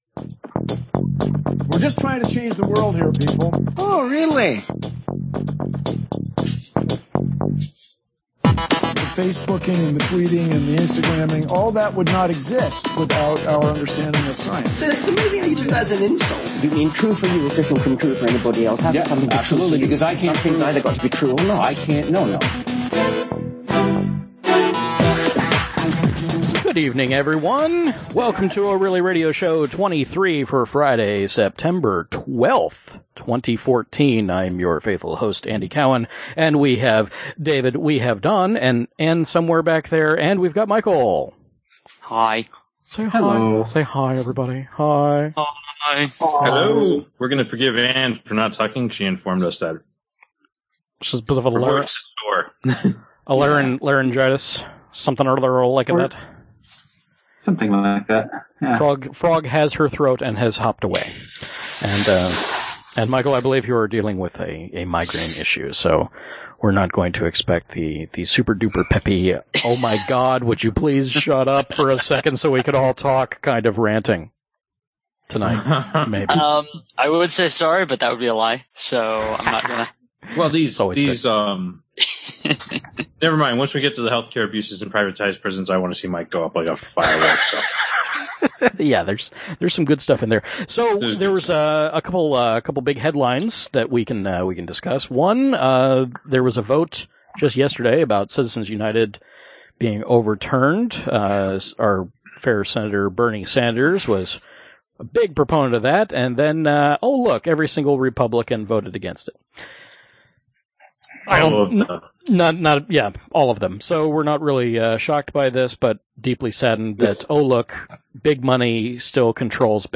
Live every Friday night at about 9pm